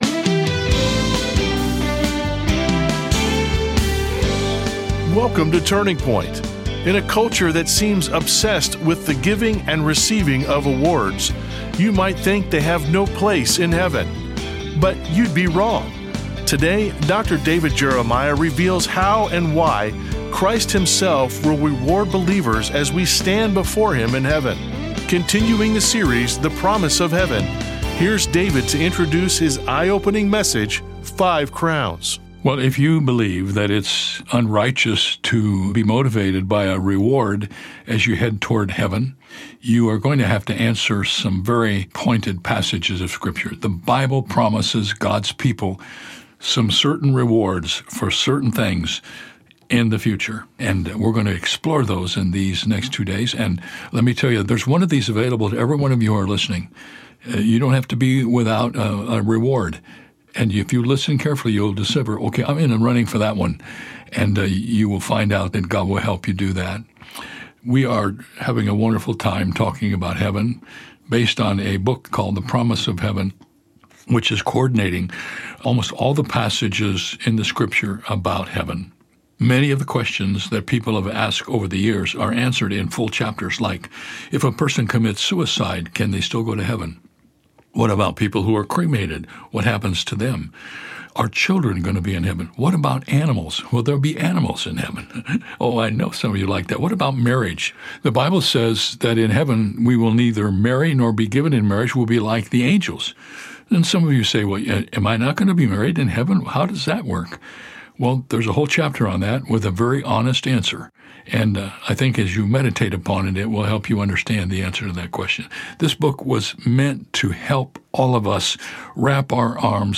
Five Crowns (Pt. 1) Podcast with David Jeremiah